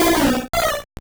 Cri de Piafabec dans Pokémon Or et Argent.